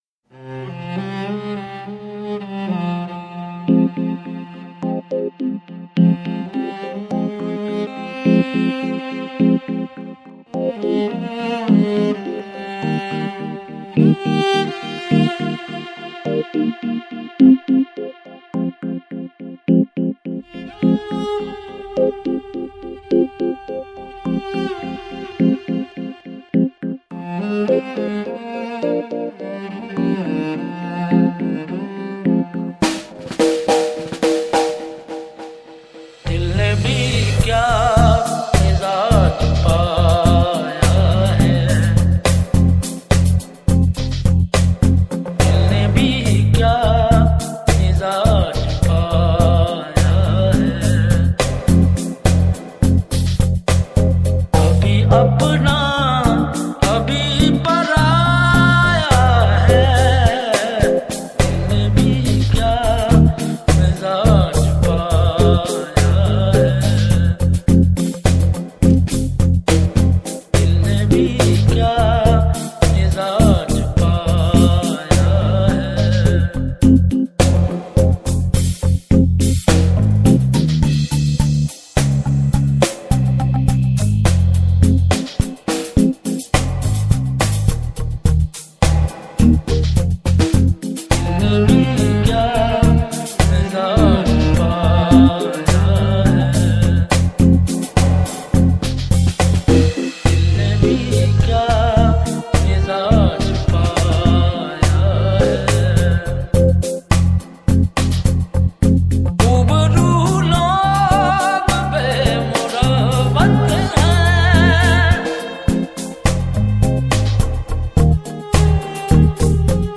Sufi Collection